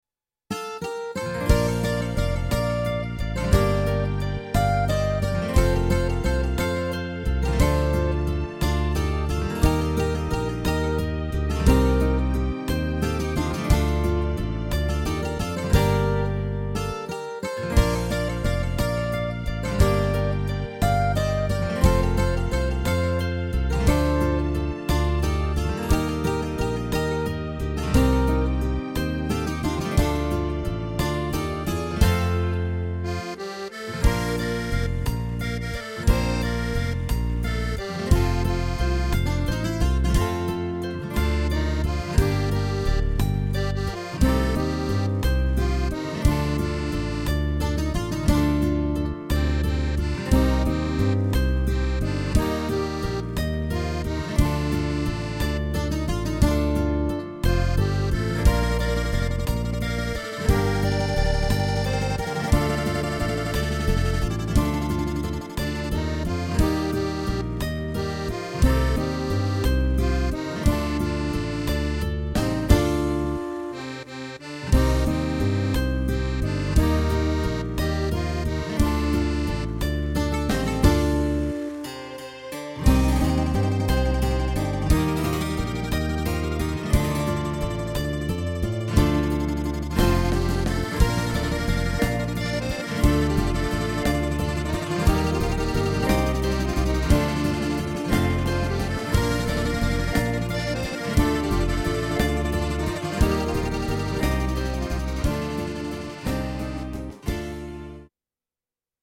Greek Ballad